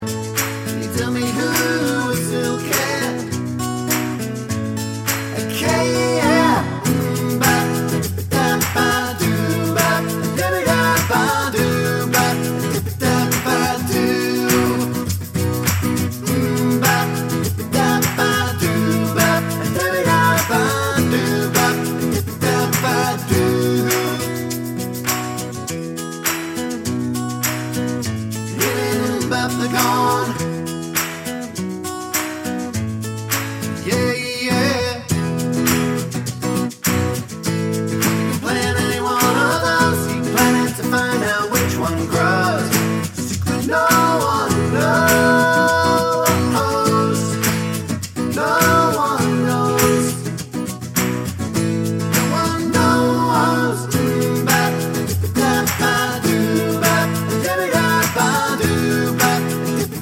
Live Acoustic Pop (1990s) 3:02 Buy £1.50